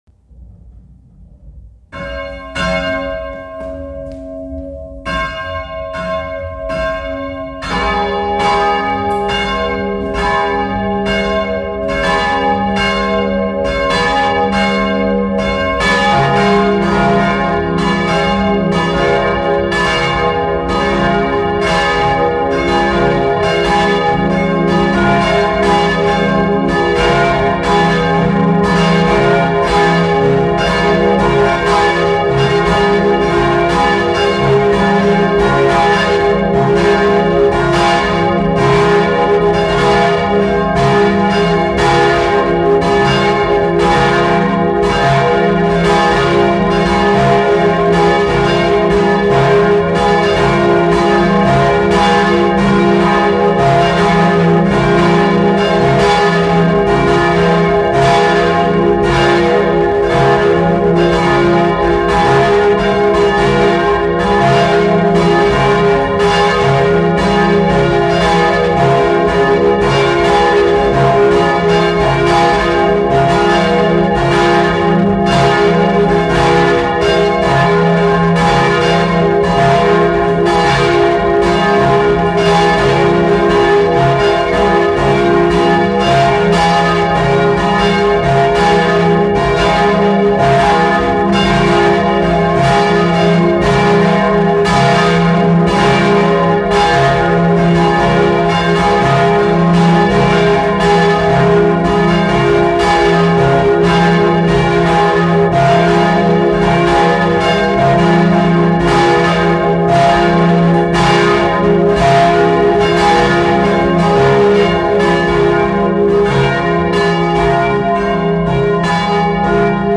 Gelaeut_Fellingshausen.mp3